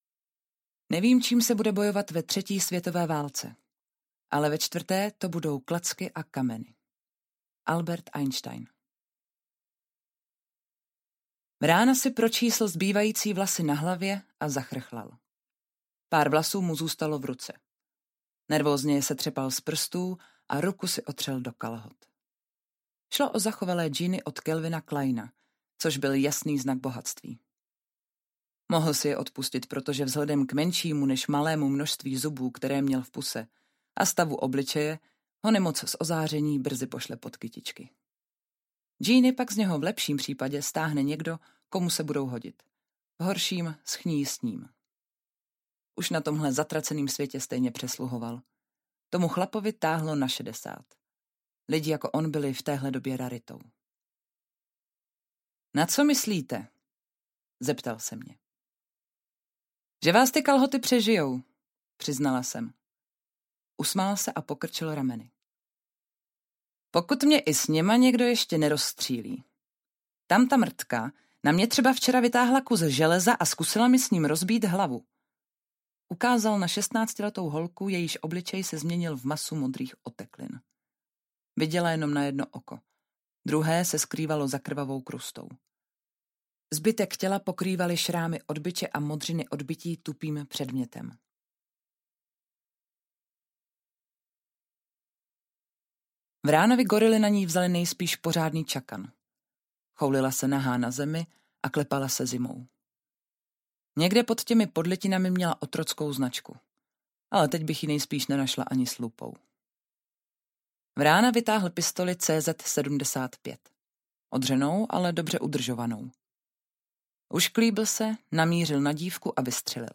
Řetězová reakce audiokniha
Ukázka z knihy